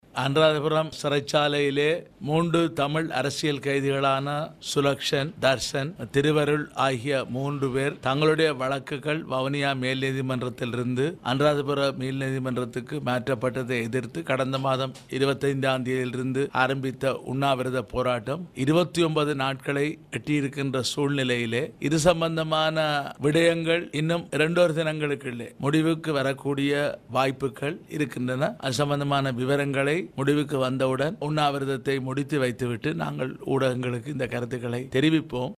இந்த தகவலை தமக்கு வடக்கு மாகாண ஆளுனர் ரெஜினோல்ட் குரே வழங்கியதாக, சிவாஜிலிங்கம் நேற்று யாழ்ப்பாணத்தில் நடைபெற்ற ஊடக சந்திப்பில் வைத்து கூறியுள்ளார்.